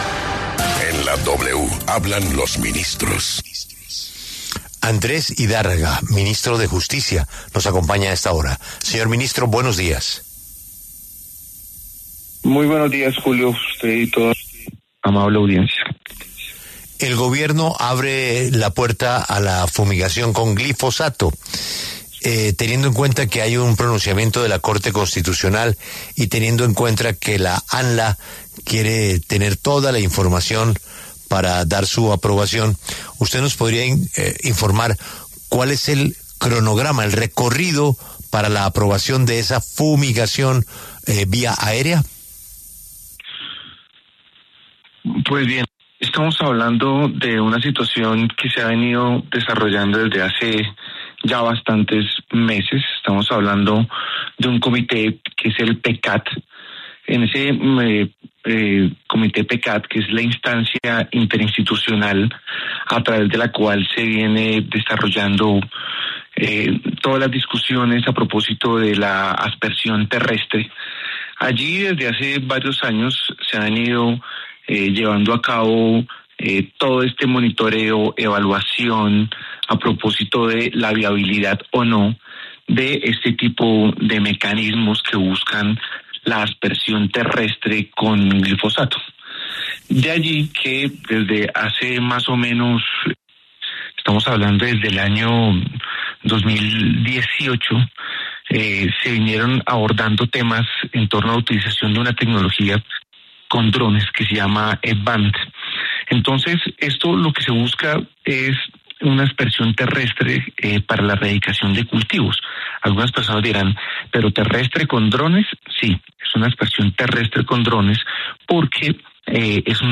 En diálogo con W Radio, el ministro de Justicia (e), Andrés Idárraga, además, anunció que el informe de medición de cultivos de coca -que publican la ONU y el ministerio- sería publicado en enero próximo.